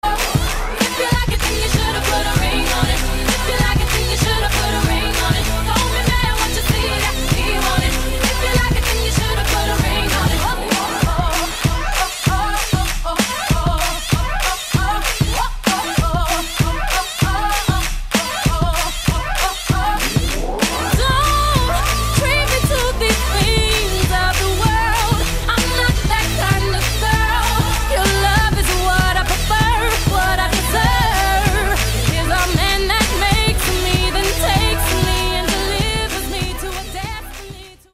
Tonos de canciones del POP